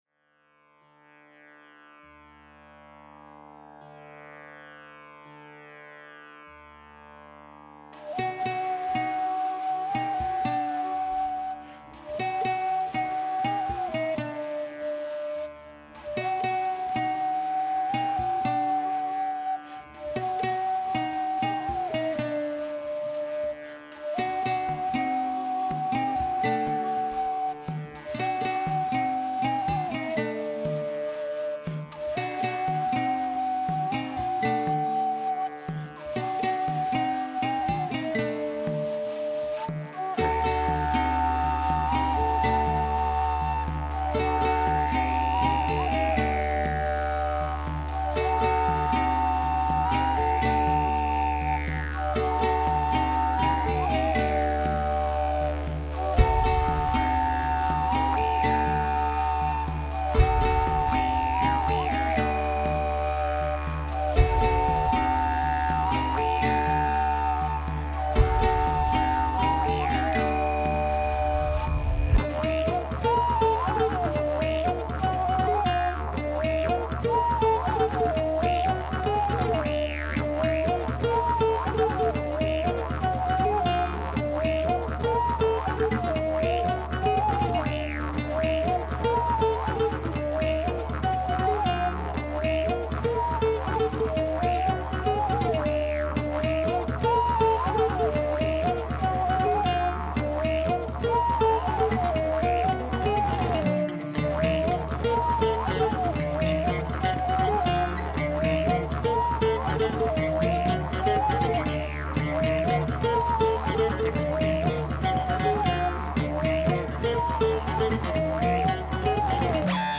Donc au menu, du didge (sans blague), de la flûte, quelques percus, de la guitare, pis des p'tites merdouilles de ci de là.
Ensuite pour les passages avec des sons un peu electro + basse, en ressent plus le coté djemdi.
:super: j'ai bien aimé, surtout l'début, certain passage éléctro suis moins fan, mais l'ensemble se tient bien ;-)
L'enregistrement et le mixage sont vraiment très bons, du monde en bas avec beaucoup d'air, c'est super classe.
La gratte donne l'impression de ne pas en être une, on dirait un oud !